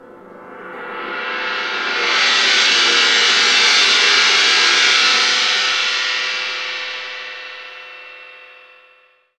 Index of /90_sSampleCDs/Roland LCDP03 Orchestral Perc/CYM_Gongs/CYM_Dragon Cymbl